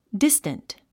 発音
dístənt　ディスタント
distant.mp3